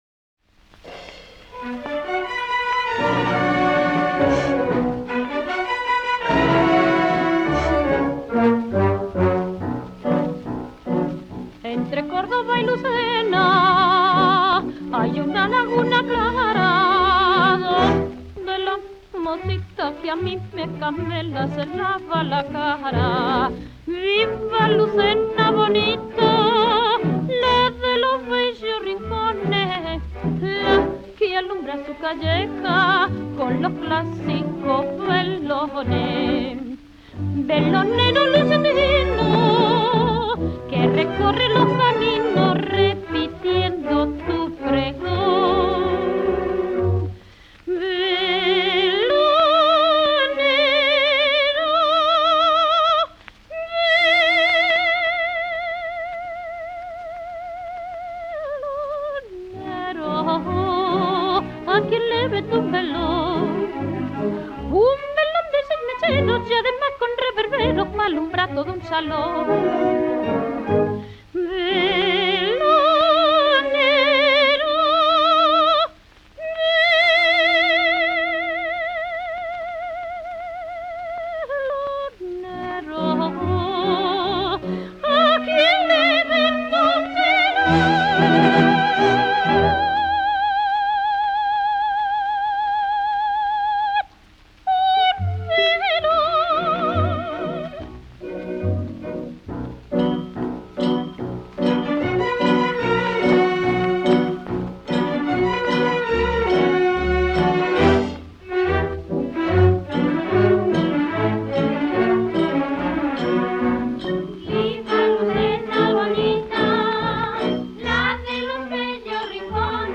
canción
78 rpm